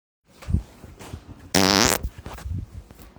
Crispy Fart - Botón de Efecto Sonoro